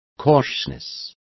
Complete with pronunciation of the translation of cautiousness.